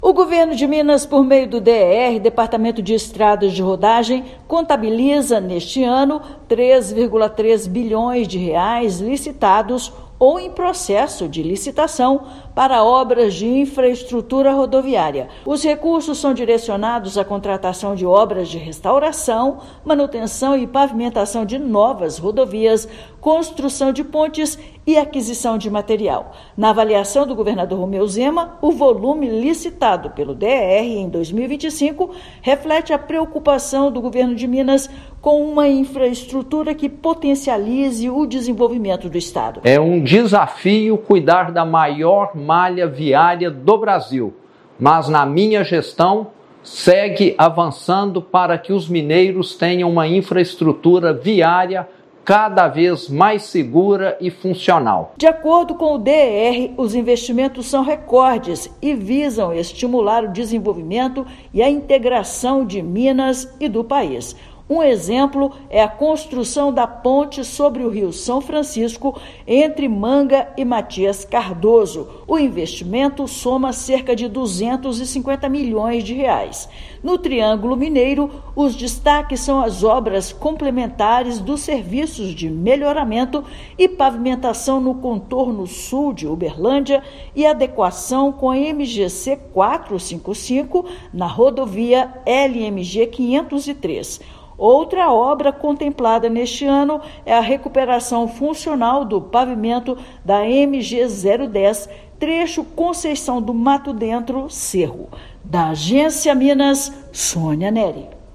Volume licitado em 2025 supera em mais de 46% o montante do ano anterior para contratação de obras e serviços. Ouça matéria de rádio.